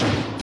Loiter Sqaud Snare.wav